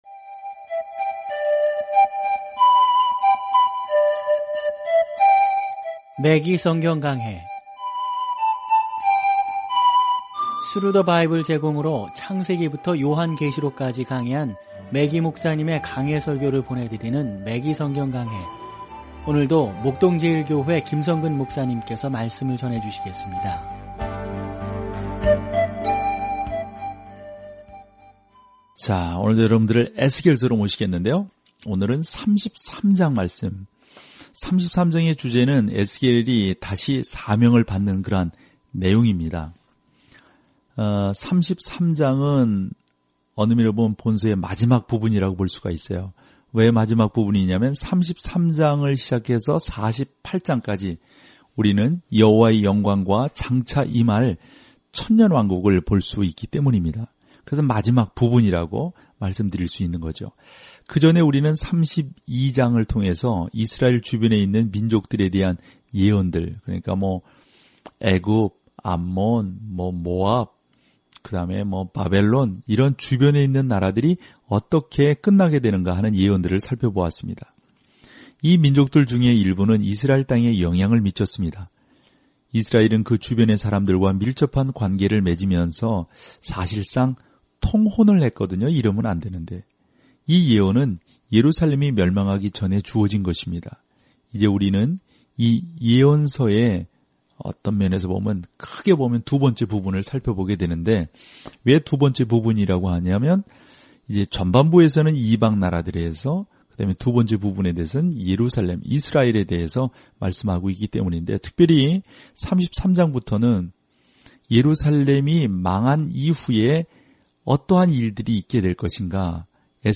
말씀 에스겔 31 에스겔 32 16 묵상 계획 시작 18 묵상 소개 백성들은 하나님께로 돌아오라는 에스겔의 경고의 말을 듣지 않고 오히려 묵시적인 비유를 행하여 백성들의 마음을 찔렀습니다. 오디오 공부를 듣고 하나님의 말씀에서 선택한 구절을 읽으면서 매일 에스겔서를 여행하세요.